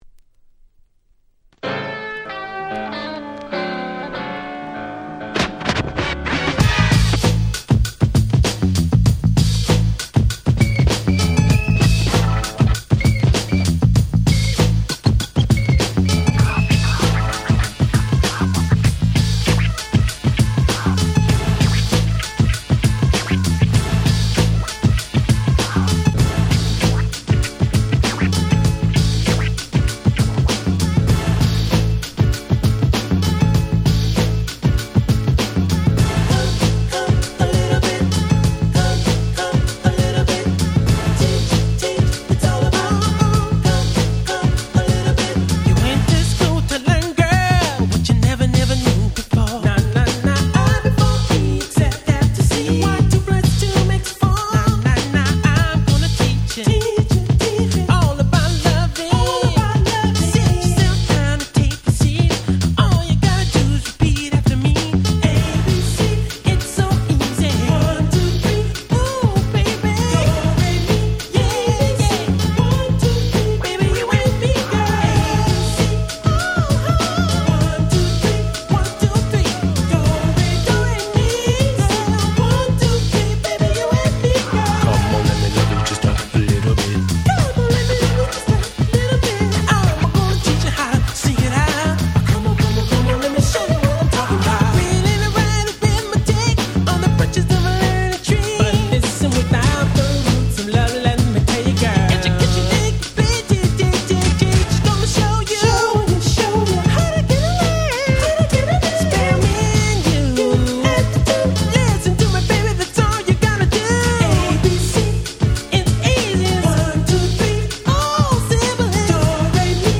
92' Very Nice Break Beats / R&B !!